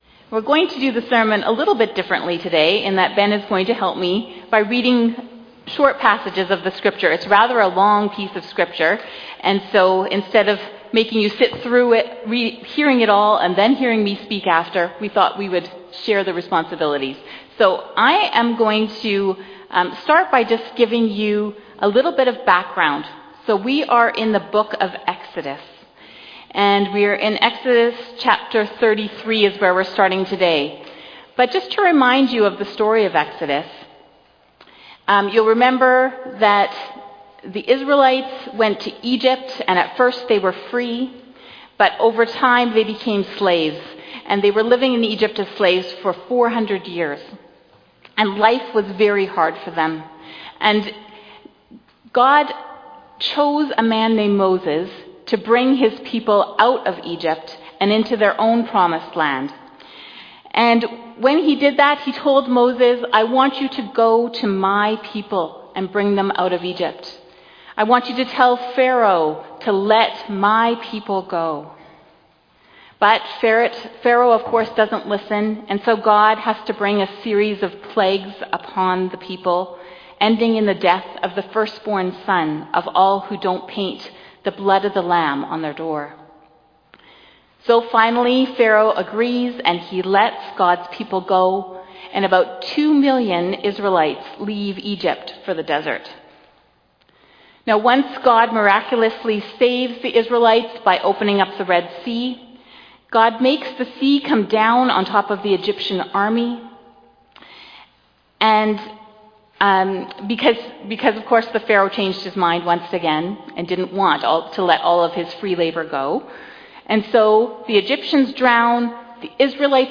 2025 Sermon September 28 2025